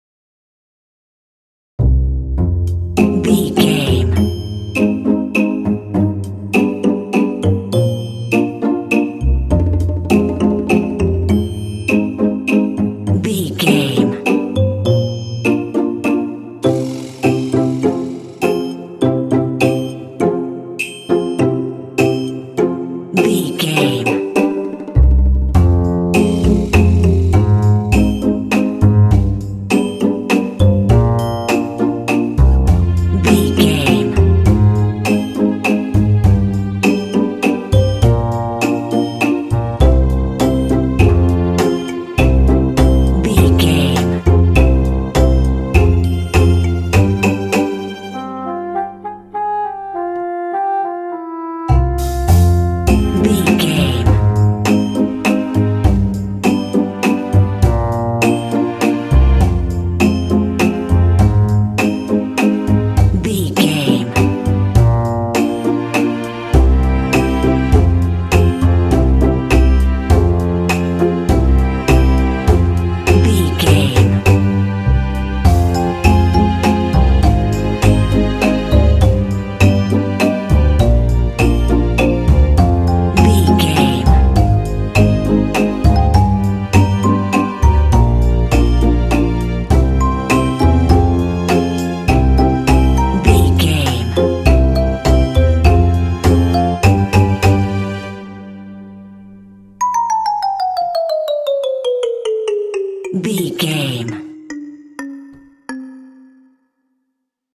Aeolian/Minor
playful
foreboding
suspense
percussion
double bass
strings
contemporary underscore